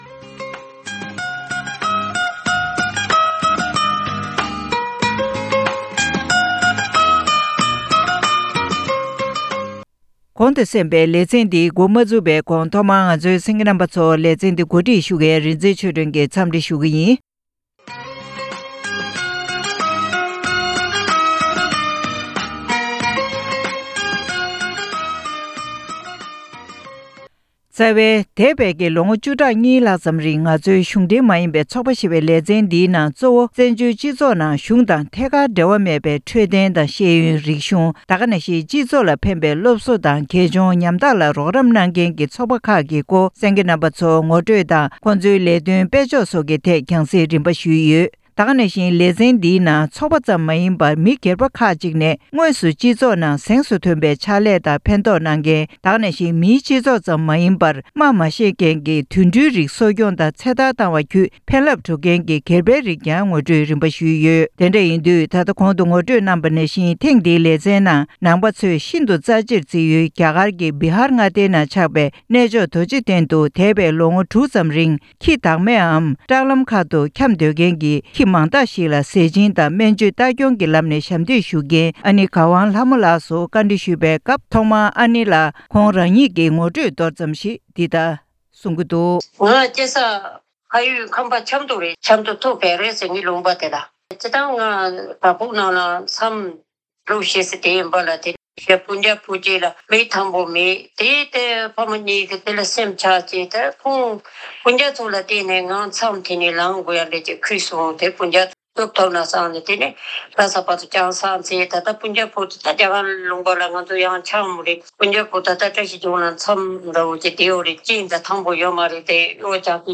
བཀའ་འདྲི་ཞུས་ཏེ་ཕྱོགས་བསྒྲིགས་ཀྱིས་སྙན་སྒྲོན་ཞུས་པར་གསན་རོགས།